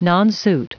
Prononciation du mot nonsuit en anglais (fichier audio)
Prononciation du mot : nonsuit